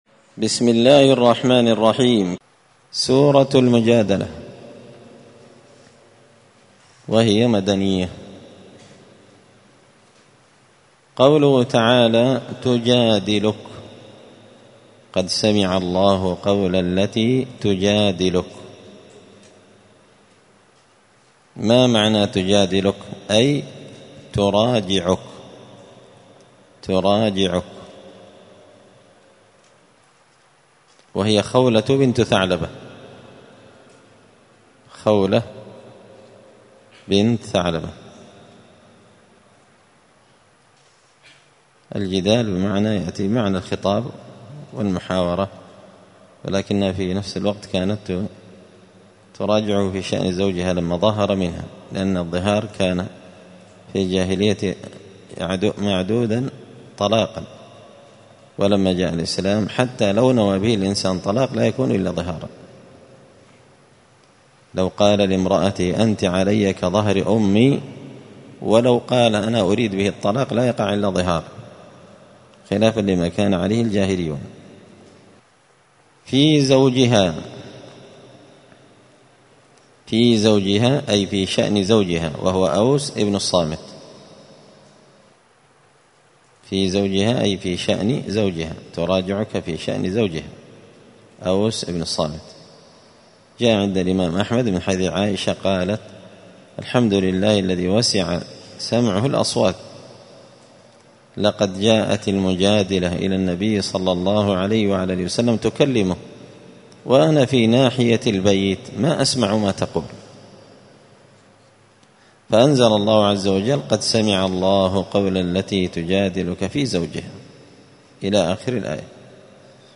زبدة الأقوال في غريب كلام المتعال الدرس العشرون بعد المائة (120)